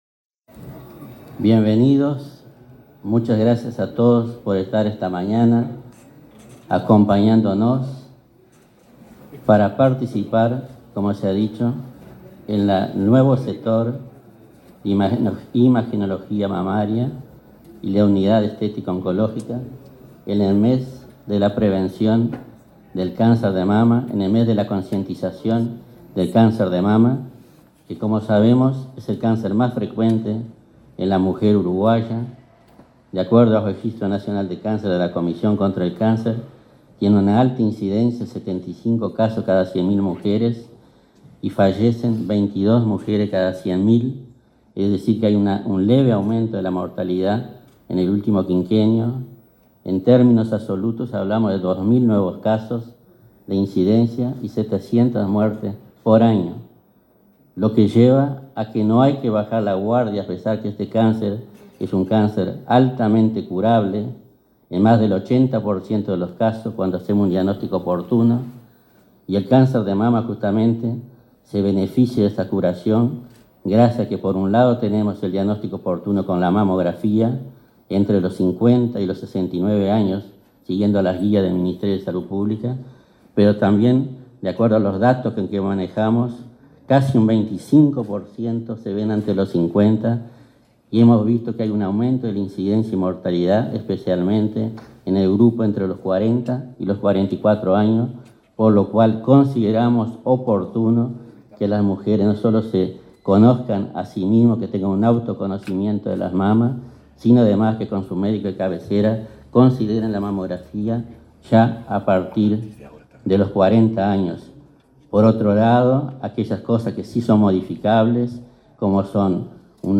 Palabras de autoridades en inauguración en INCA
El titular de este organismo, Robinson Rodríguez, y el presidente del prestador de salud, Leonardo Cipriani, señalaron la importancia de estas nuevas herramientas médicas.